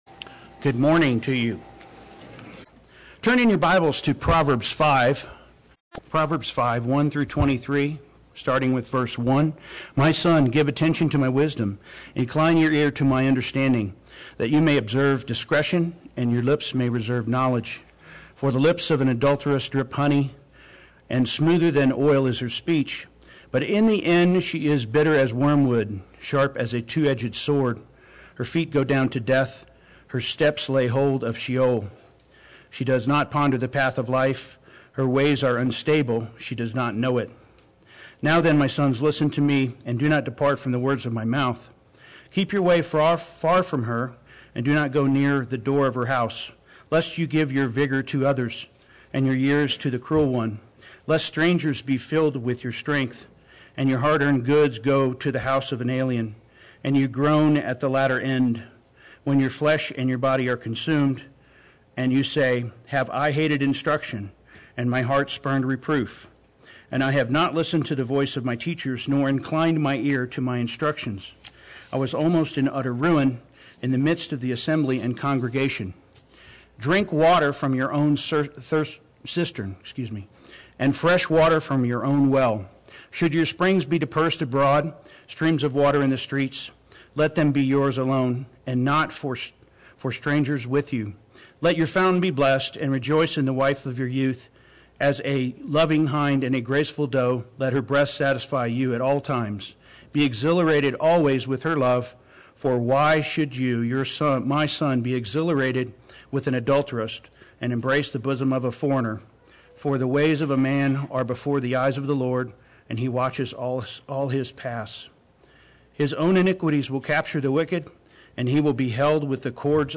Play Sermon Get HCF Teaching Automatically.
Before the Eyes of the Lord Sunday Worship